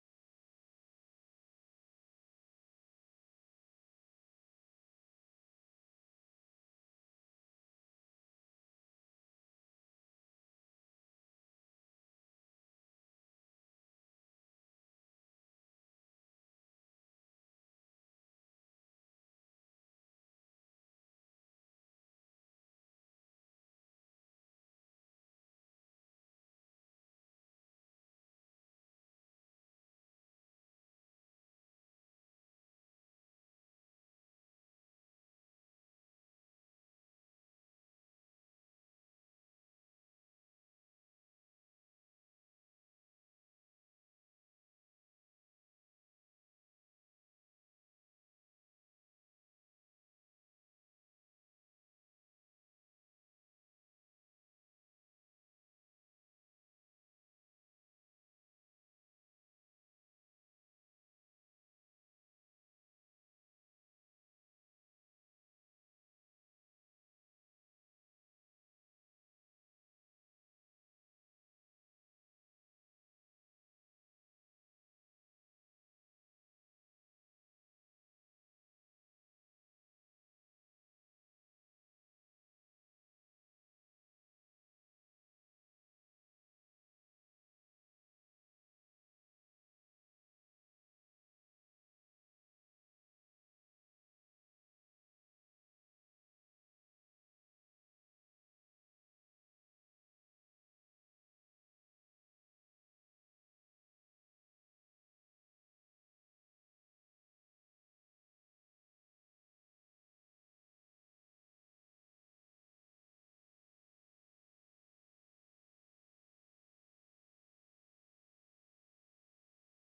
Ruim 800 deelnemers kwamen naar Congrescentrum 1931 in Den Bosch.